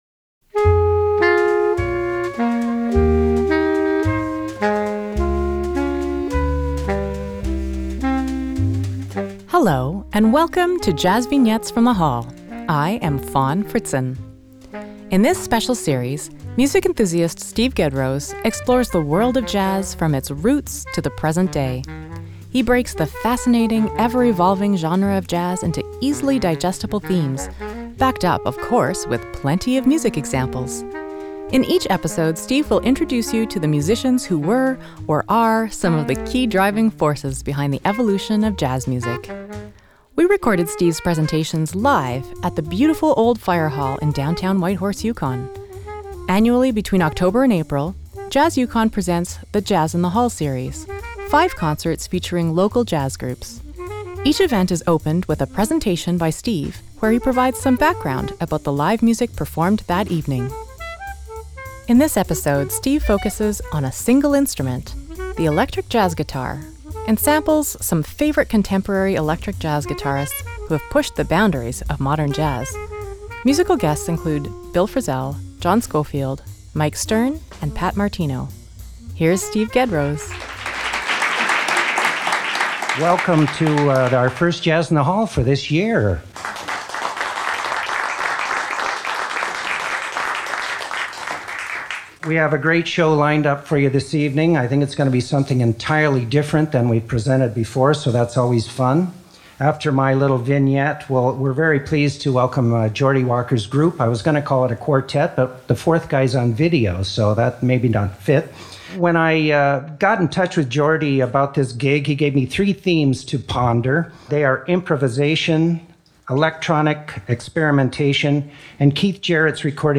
JVFTH11ElectricJazzGuitar.mp3 57,844k 256kbps Stereo Comments
JVFTH11ElectricJazzGuitar.mp3